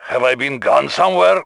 Here are some additional Boris voicelines